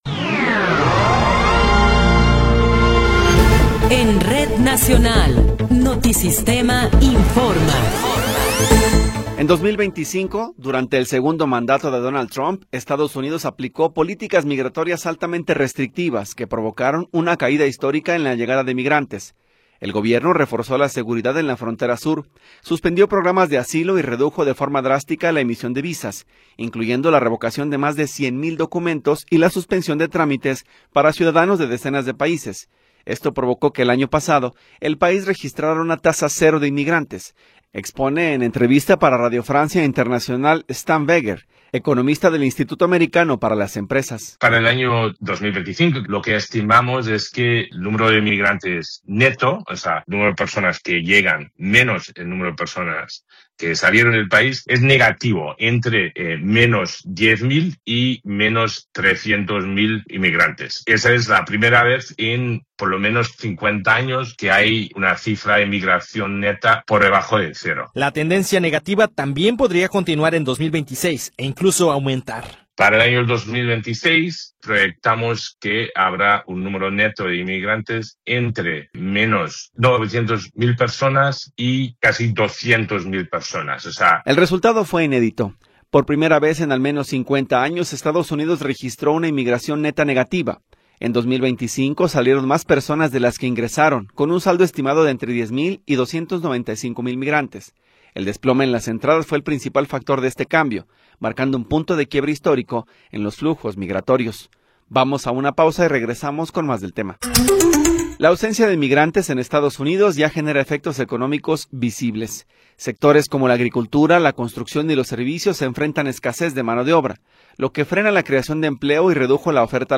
Noticiero 13 hrs. – 25 de Enero de 2026
Resumen informativo Notisistema, la mejor y más completa información cada hora en la hora.